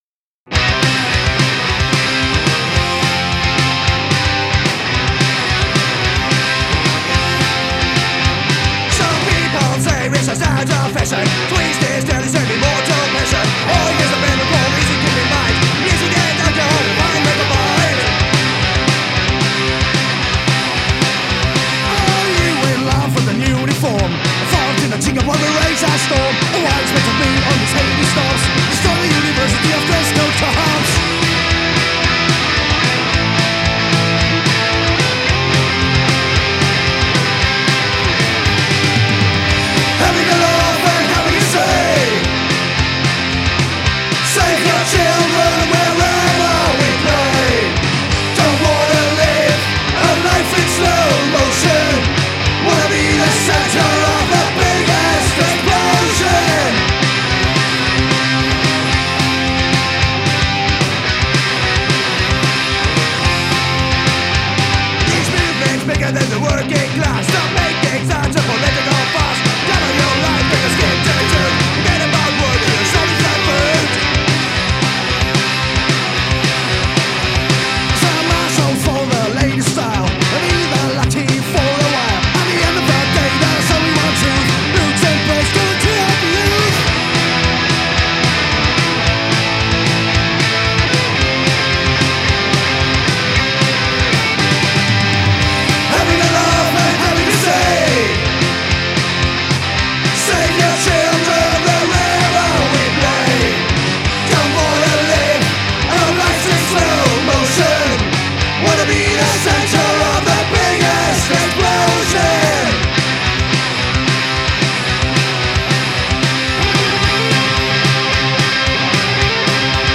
arschkickenden Punk des neuen Jahrhunderts stehen werden!
16 abwechslungsreiche Street-Hymnen